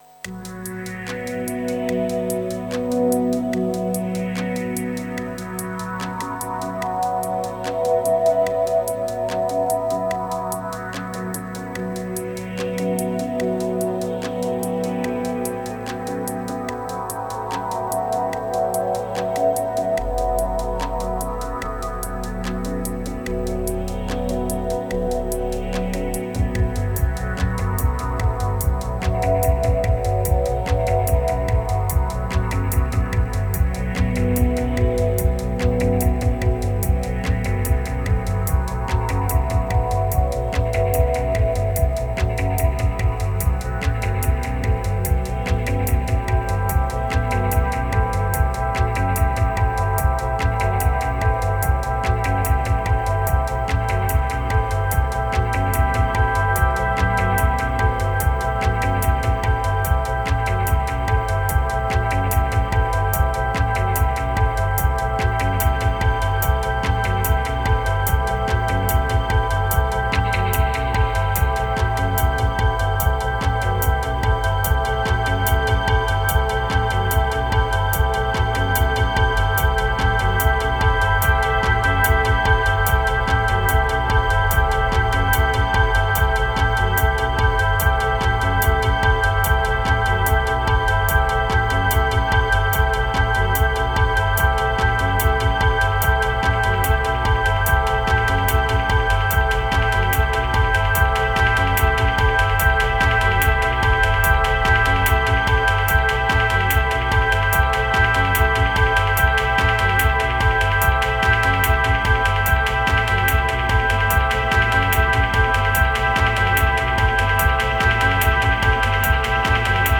Let’s cook with a sampler.
1398📈 - 93%🤔 - 73BPM🔊 - 2022-09-06📅 - 442🌟